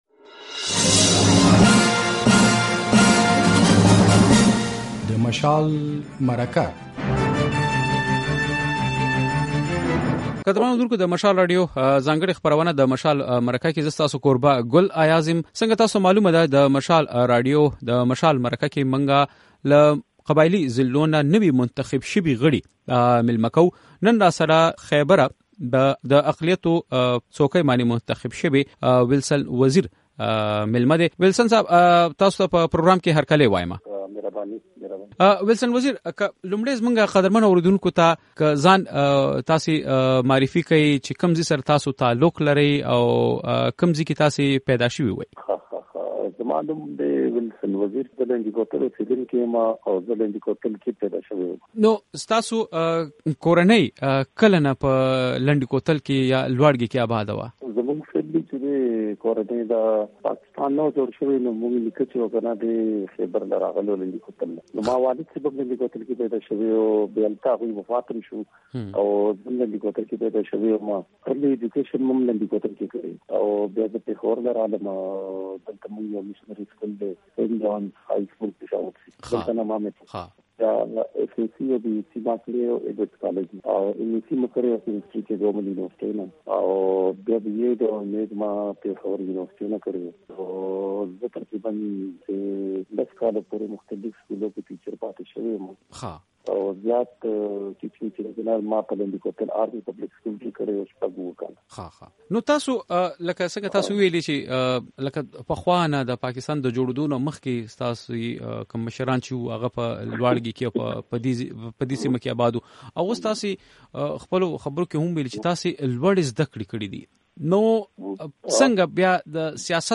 له وېلسن وزیر سره د مشال مرکه
دا اوونۍ په مشال مرکه کې له وېلسن وزیر سره غږېدلي یو. نوموړی د خيبر پښتونخوا په اسمبلۍ کې د اقليتونو استازی دی.